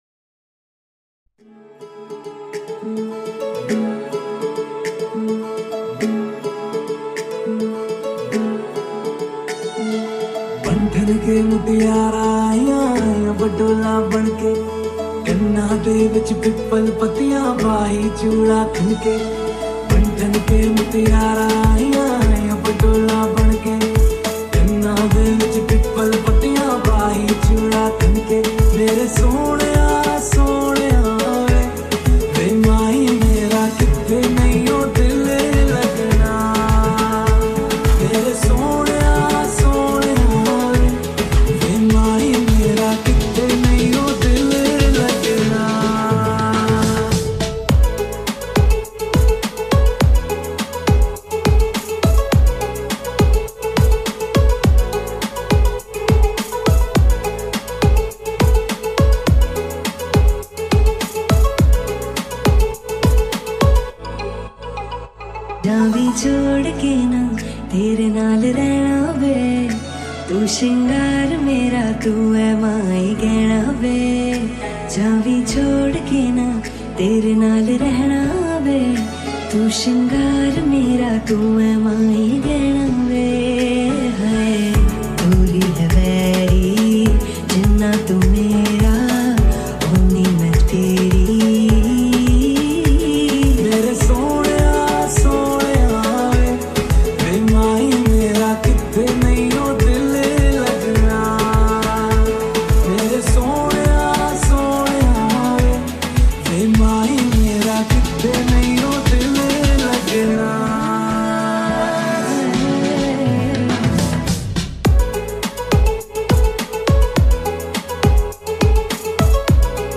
EDM Remix